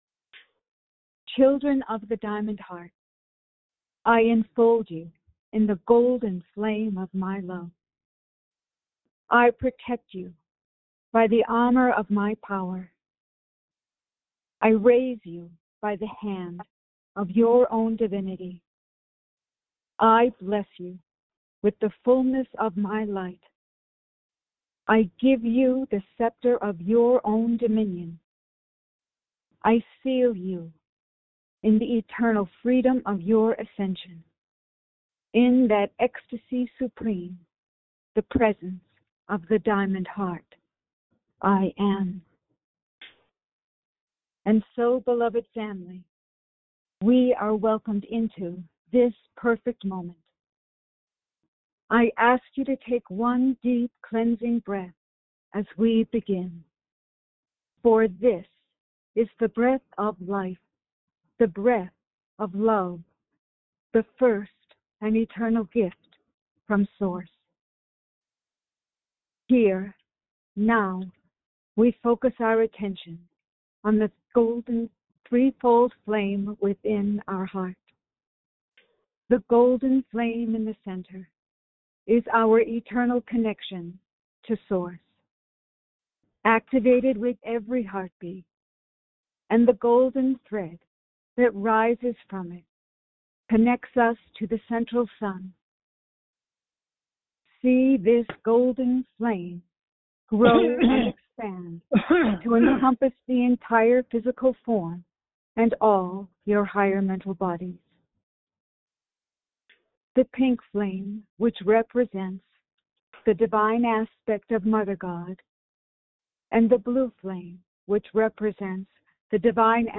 Join in group meditation with Master Saint Germain.